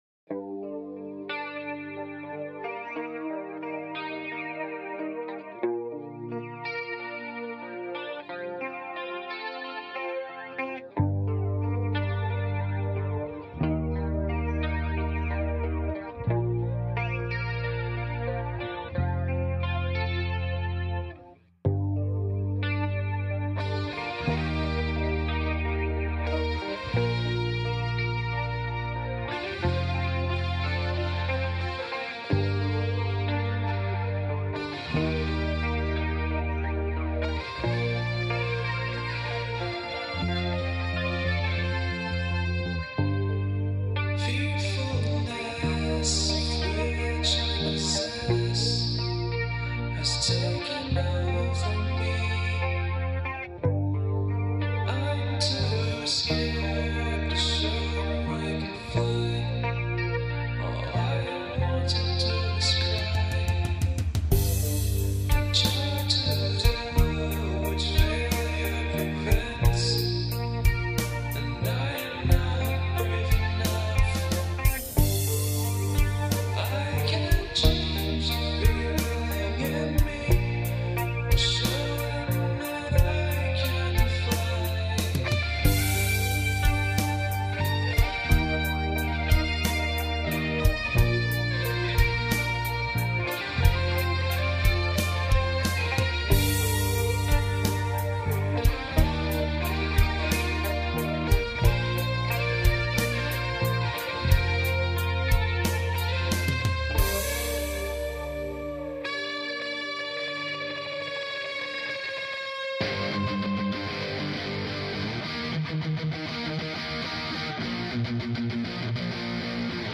Guitar, bass, dubbed drumset, and vocals